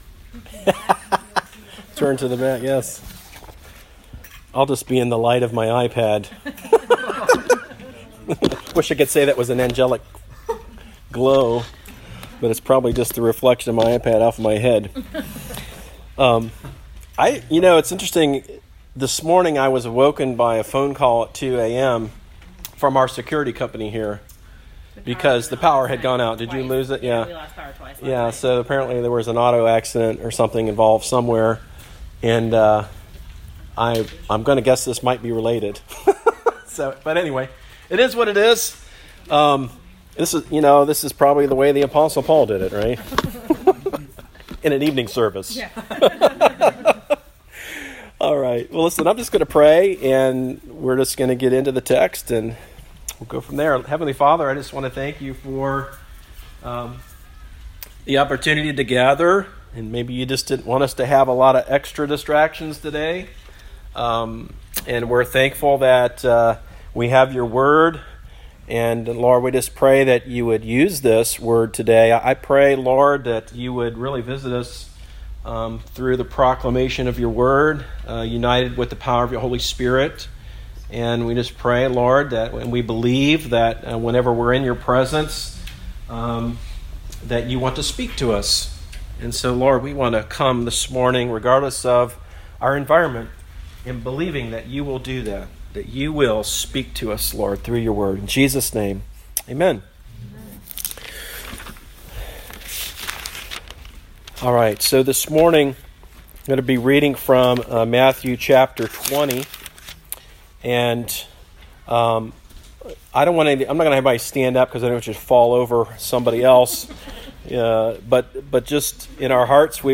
Recent Sermon - Darby Creek Church - Galloway, OH